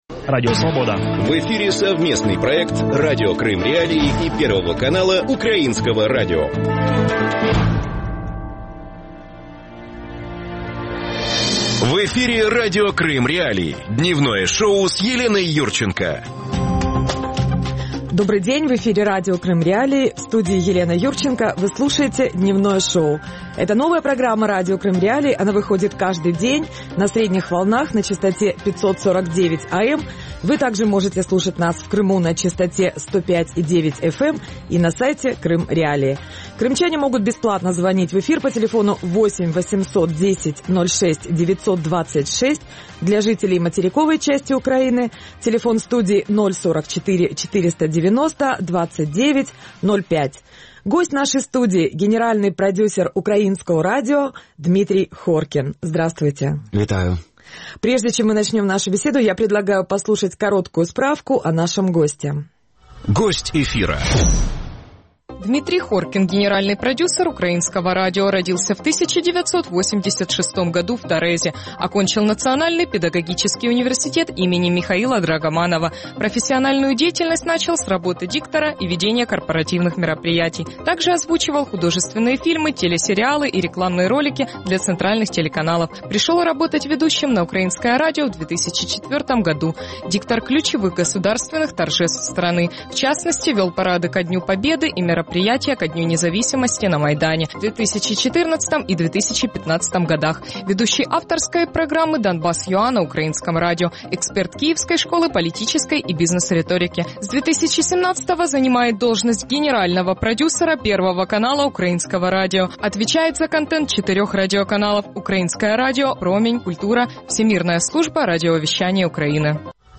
Какой запрос в Крыму на украинское теле и радиовещание? Гость студии